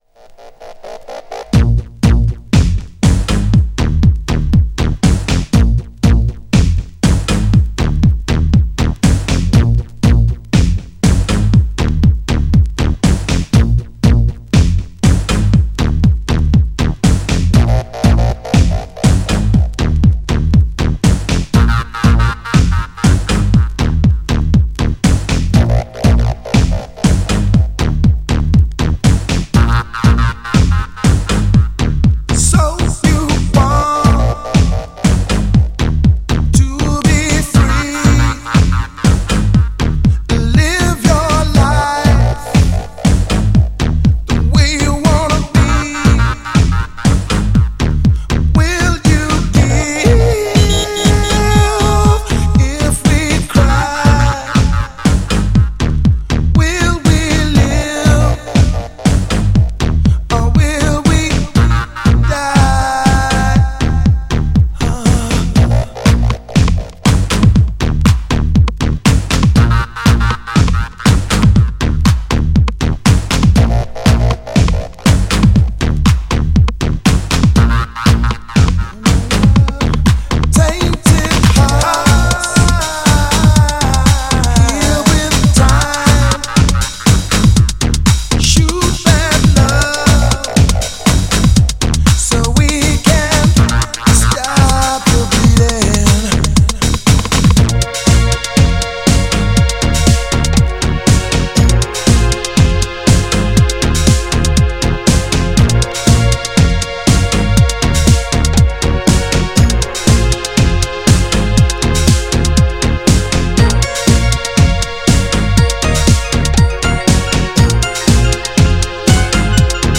GENRE House
BPM 116〜120BPM
# HARDだけどDEEP # HOUSE_CLASSIC
# ダーク # テクノ # ブロークンビーツ # プログレッシブ